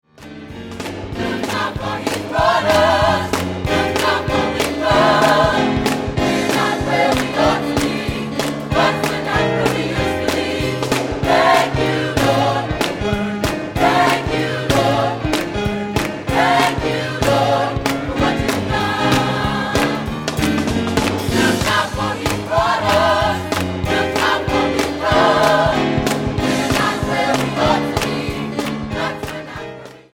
keyboard
drums